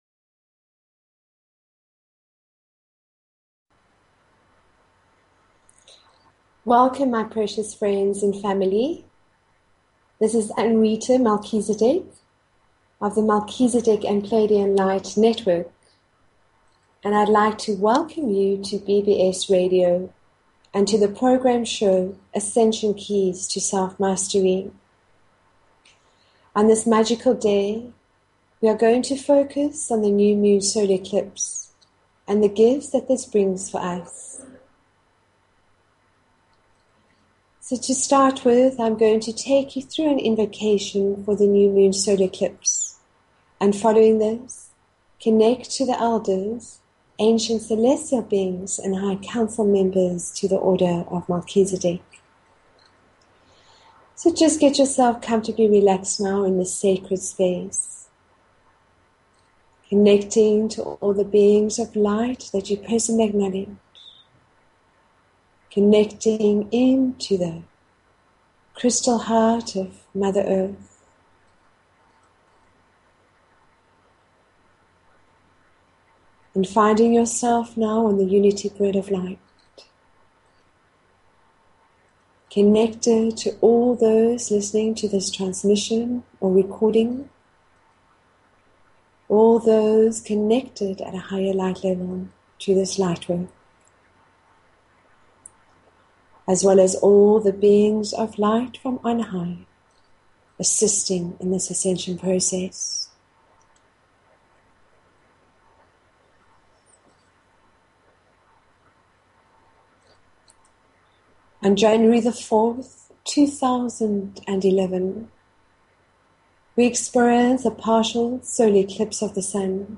Talk Show Episode, Audio Podcast, Ascension_Keys_to_Self_Mastery and Courtesy of BBS Radio on , show guests , about , categorized as